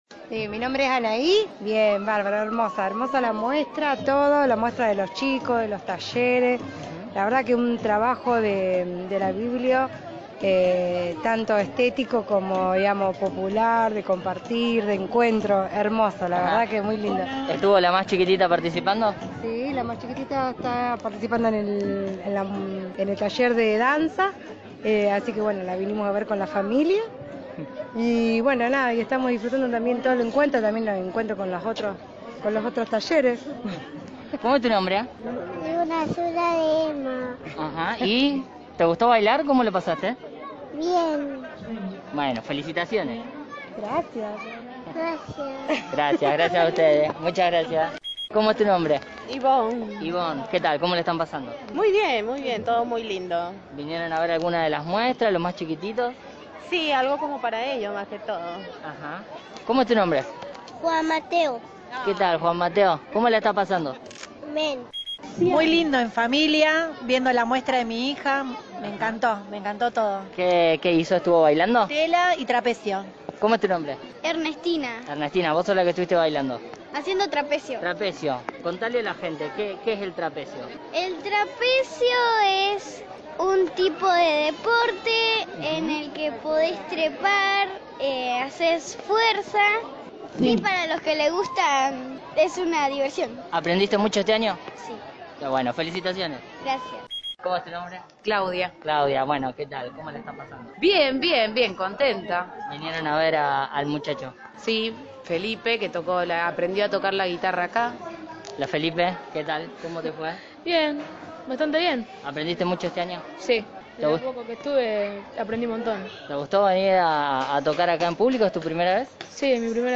Algunos testimonios de los padres y vecinos que se acercaron a compartir la jornada